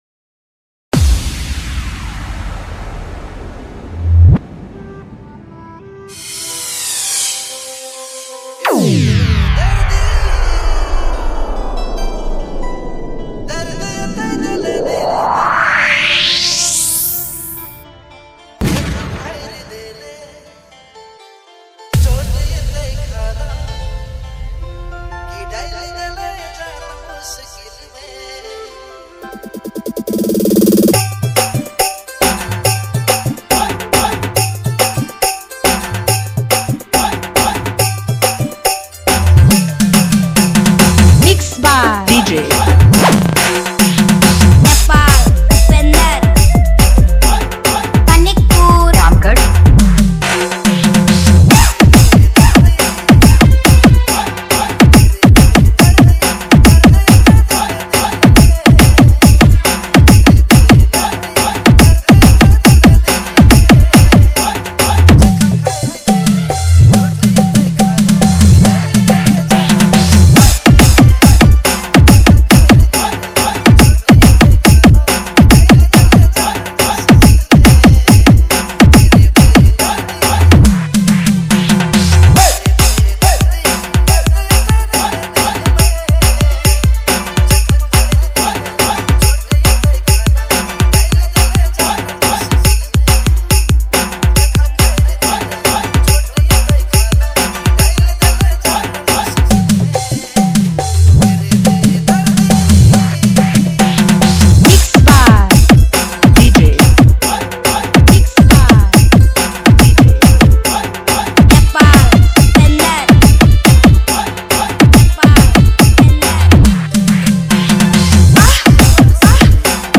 poignant Nagpuri song